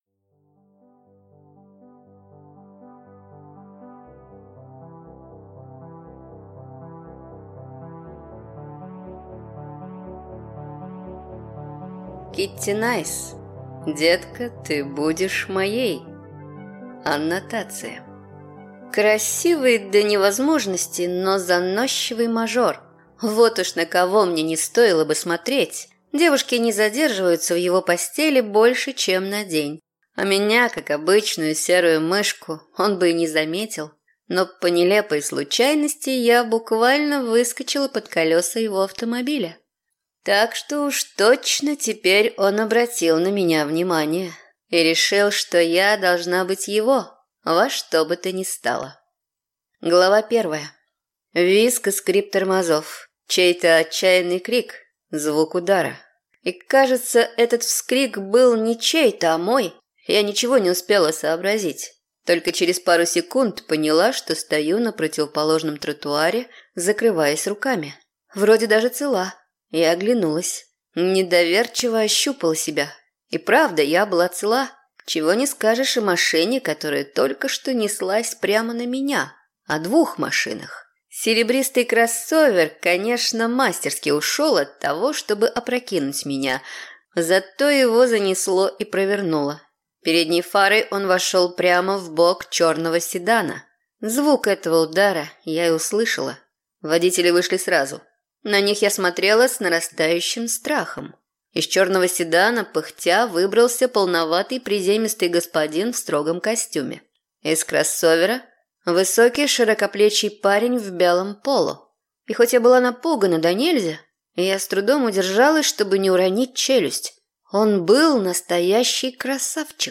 Аудиокнига Детка, ты будешь моей | Библиотека аудиокниг